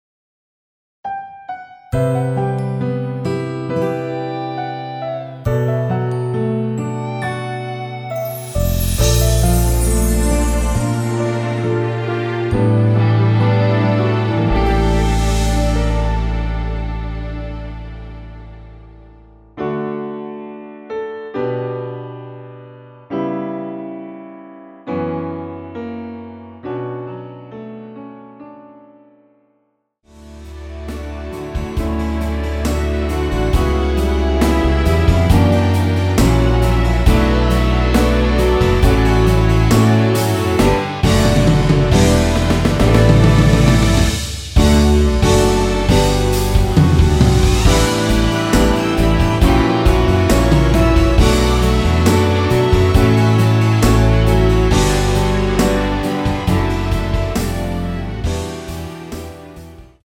내린 MR
◈ 곡명 옆 (-1)은 반음 내림, (+1)은 반음 올림 입니다.
앞부분30초, 뒷부분30초씩 편집해서 올려 드리고 있습니다.
중간에 음이 끈어지고 다시 나오는 이유는